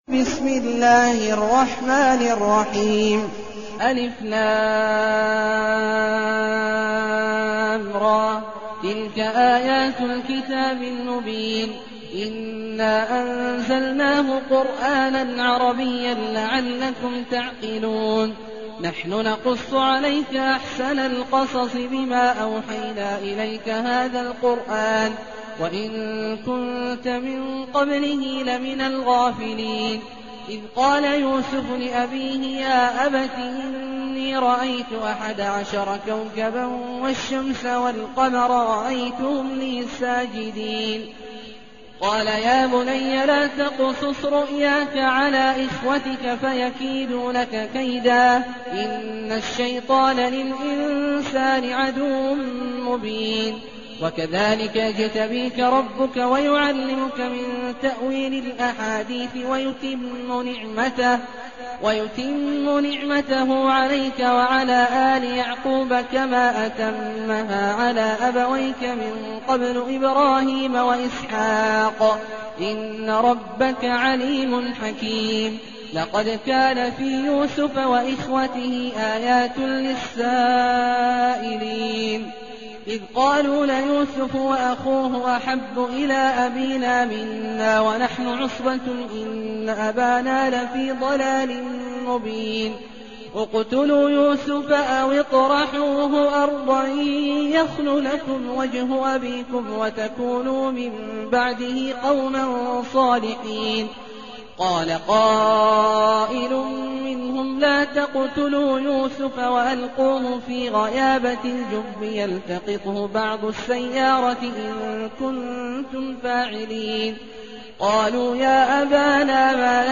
المكان: المسجد النبوي الشيخ: فضيلة الشيخ عبدالله الجهني فضيلة الشيخ عبدالله الجهني يوسف